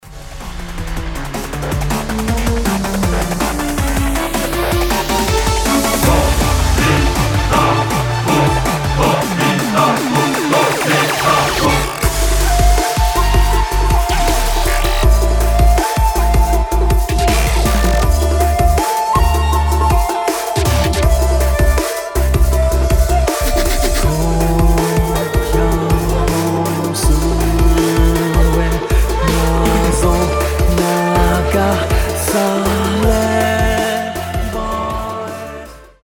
инструментальные , громкие
эпичные